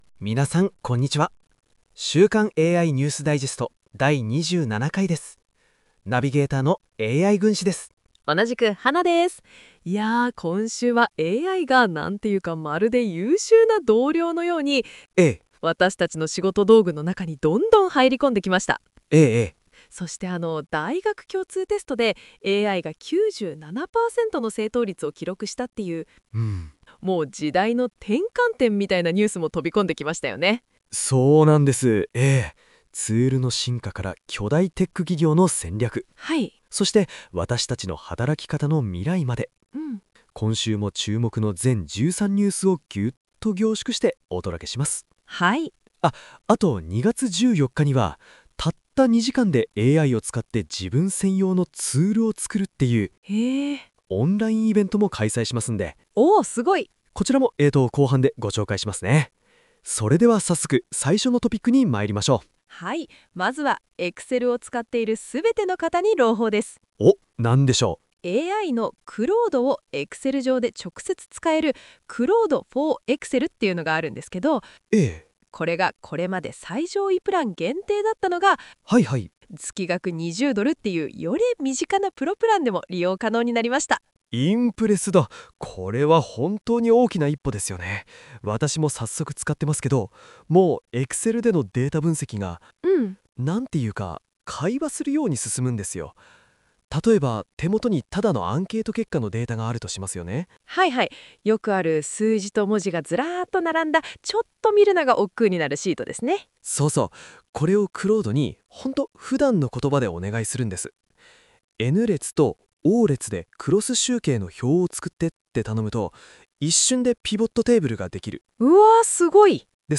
（男性）
（女性）の掛け合い形式